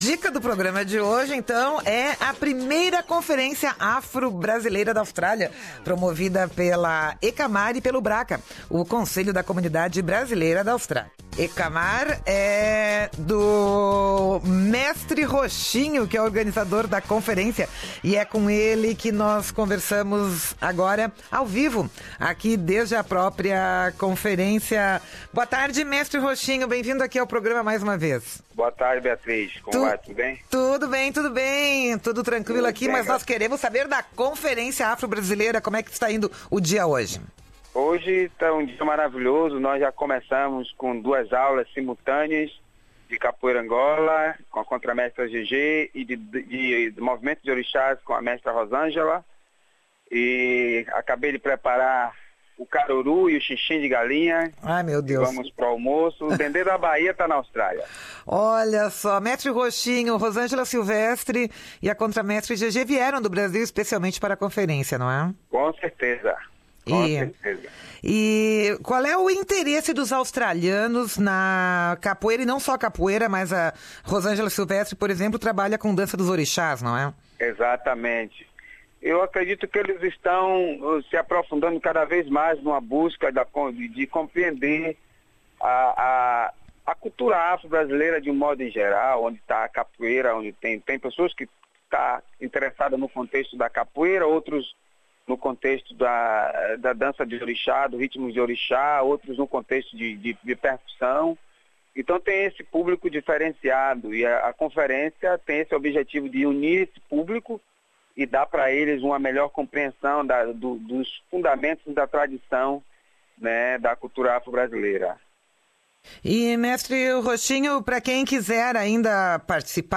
fala, ao vivo, desde a conferência.